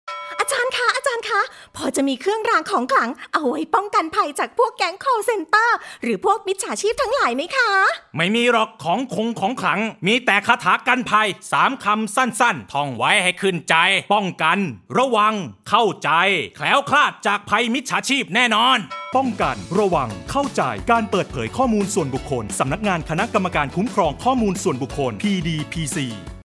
ภาคกลาง
ภาคกลาง.mp3